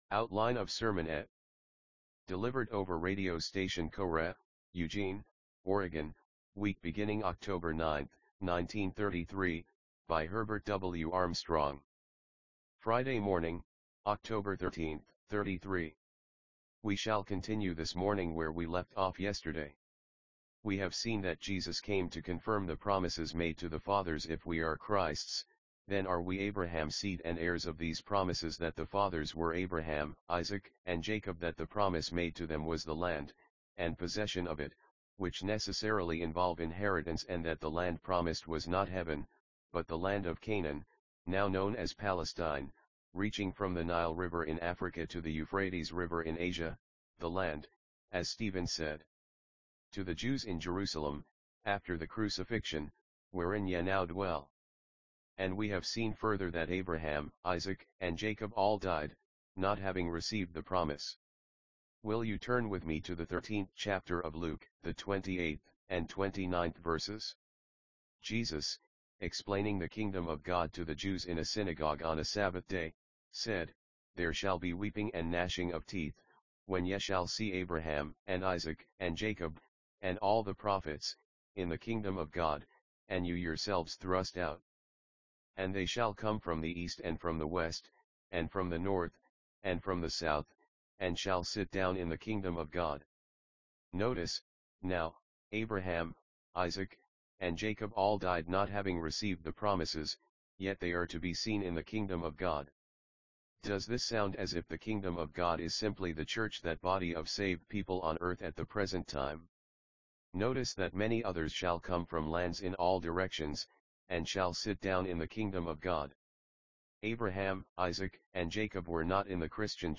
NB: a program was used to convert each of these broadcasts to MP3.